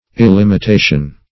Search Result for " illimitation" : The Collaborative International Dictionary of English v.0.48: Illimitation \Il*lim`it*a"tion\, n. [Pref. il- not + limitation: cf. F. illimitation.] State of being illimitable; lack of, or freedom from, limitation.